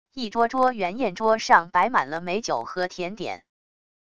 一桌桌圆宴桌上摆满了美酒和甜点wav音频生成系统WAV Audio Player